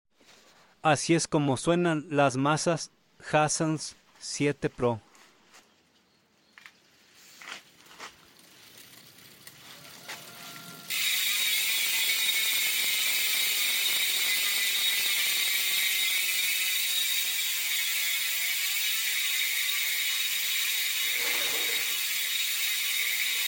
para los que querían saber cómo suenan las masas Hassns puestas🤝